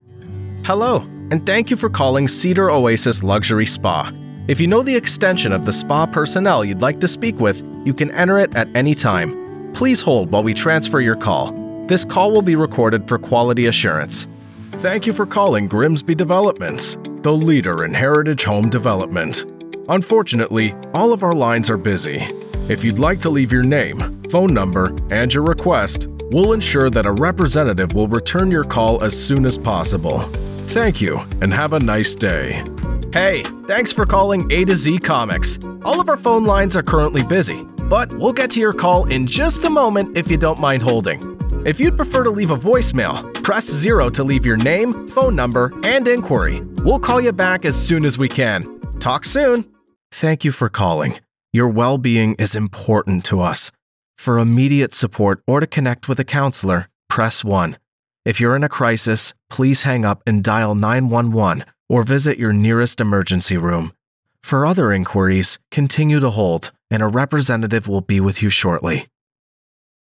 IVR
All recordings are done in my professional sound-treated vocal booth.